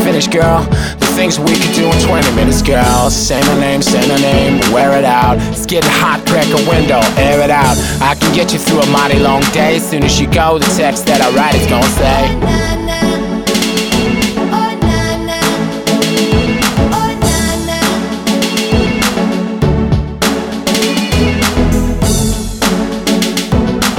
For Solo Female R'n'B / Hip Hop 4:26 Buy £1.50